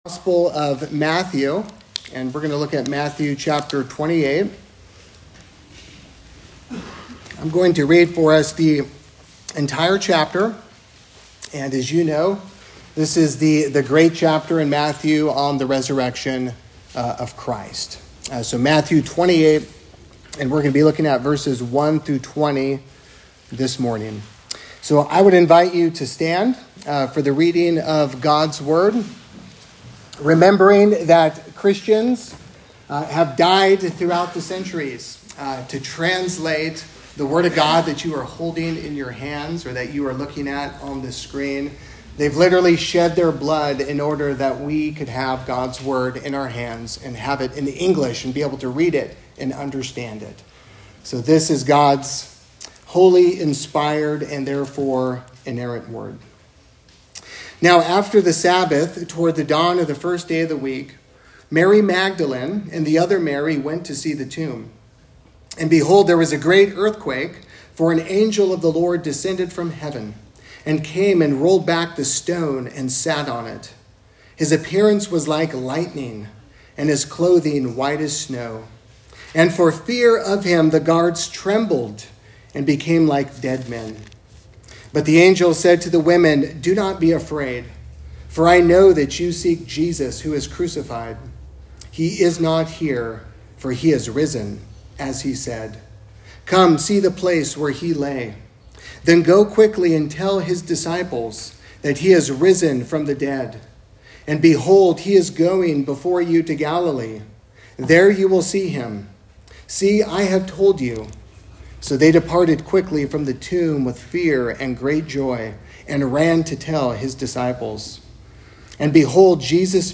3Rivers Presbyterian Church - Sermons